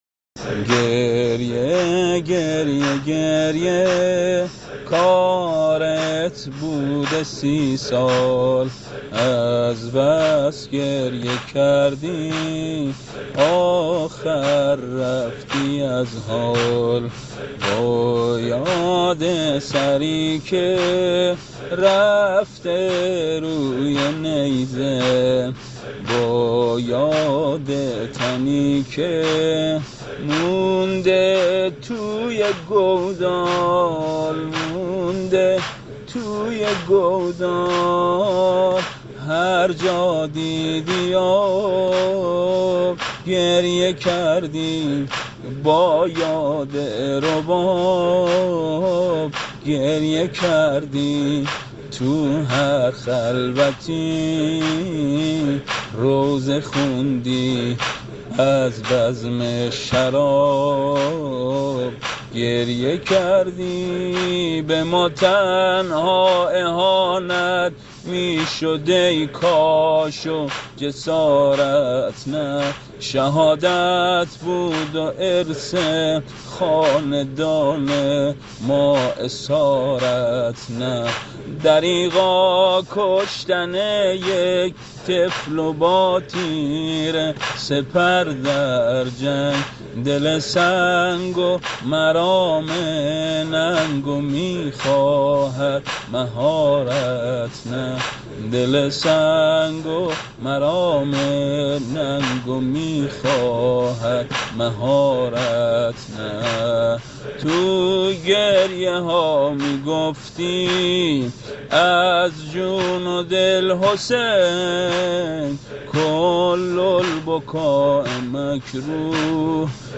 شعر سبک زمینه شهادت امام سجاد (ع) -(گریه، گریه، گریه...، کارت بوده سی‌سال)